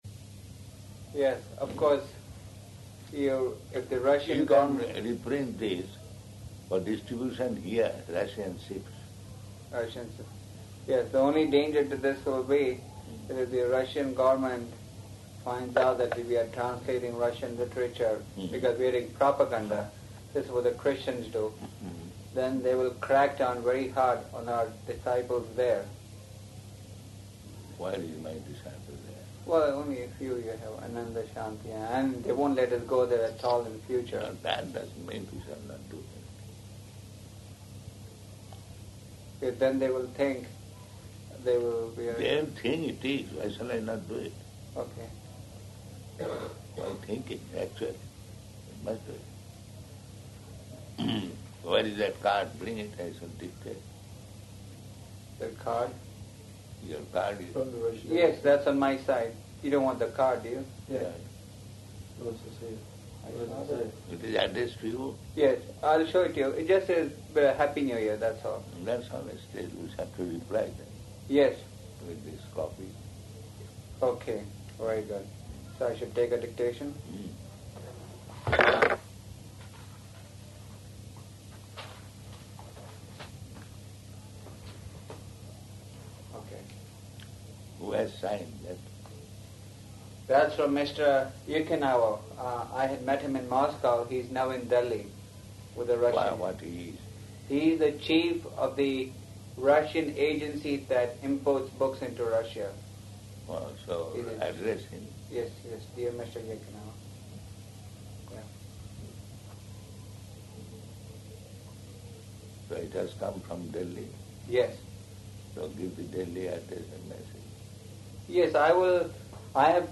-- Type: Conversation Dated: January 5th 1977 Location: Bombay Audio file